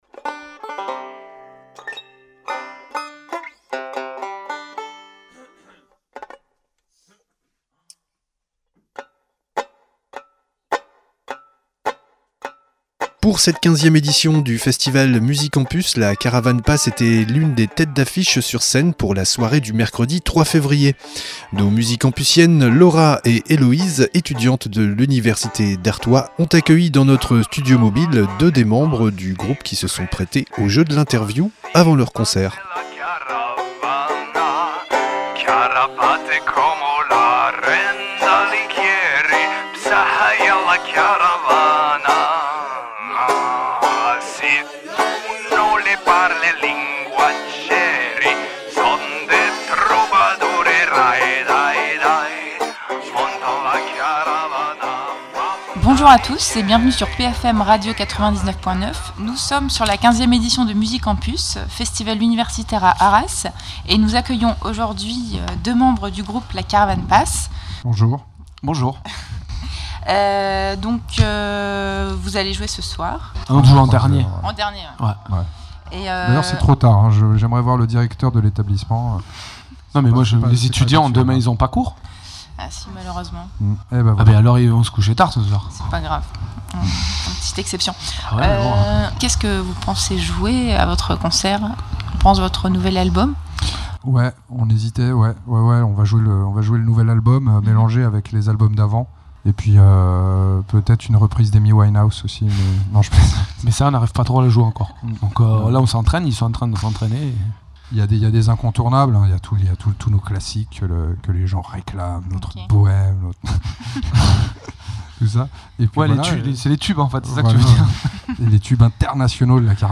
- L’interview + (pas de) live ICI